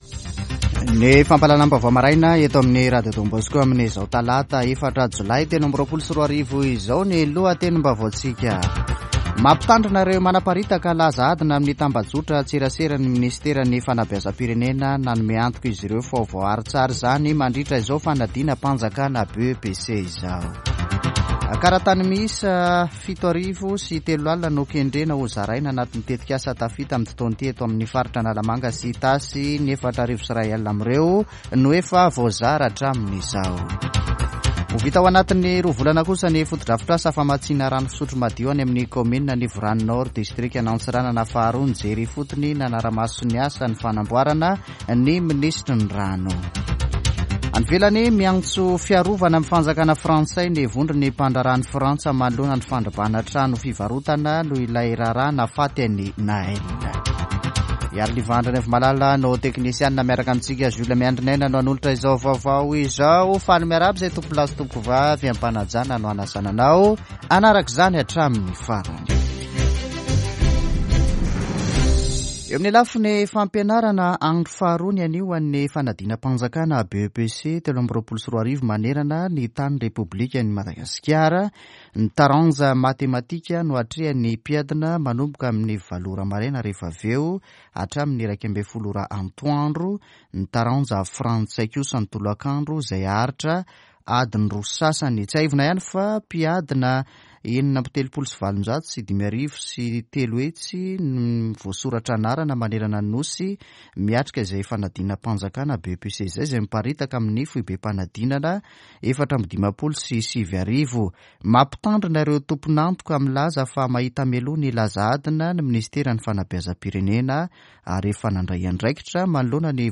[vaovao maraina] Talata 4 jolay 2023